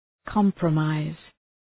Προφορά
{‘kɒmprə,maız}